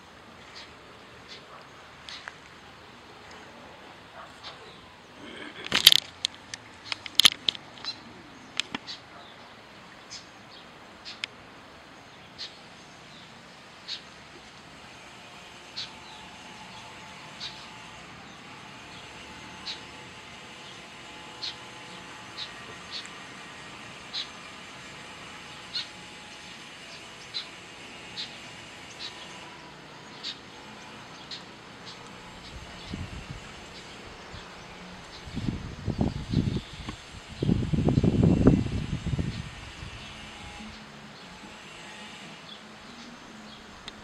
White-tipped Plantcutter (Phytotoma rutila)
"juvenil siendo alimentado por adulto" vocalizacion del juvenil
Life Stage: Juvenile
Country: Argentina
Condition: Wild
Certainty: Photographed, Recorded vocal
cortarramas-juvenil.mp3